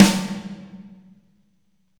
taiko-normal-hitclap.ogg